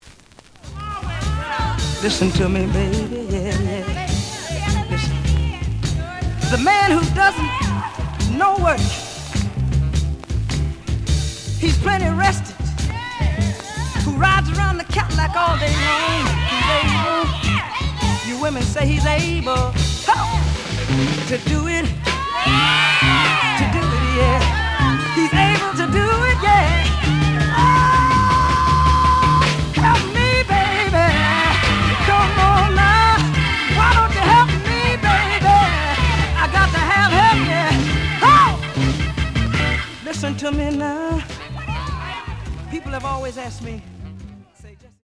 試聴は実際のレコードから録音しています。
●Genre: Soul, 60's Soul
●Record Grading: VG- (両面のラベルにダメージ。両面の盤に曇り。)